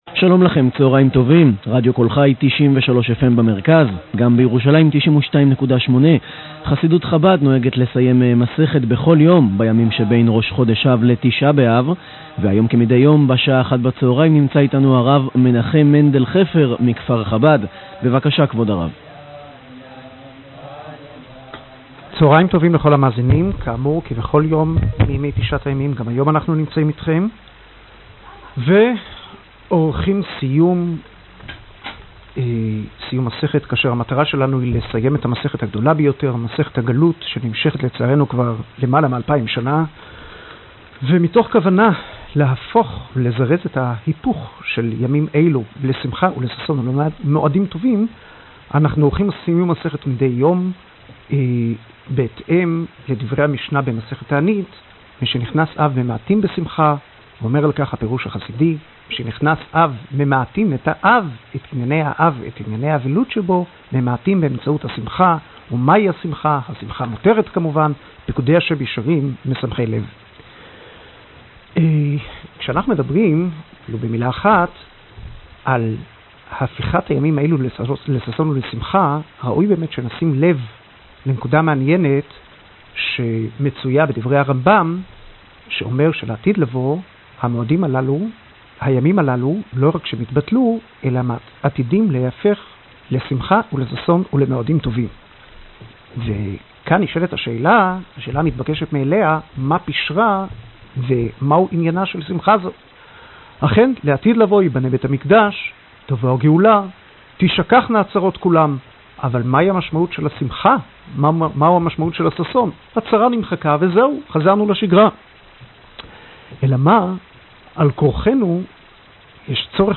סיום מסכת ברדיו 'קול חי' / אודיו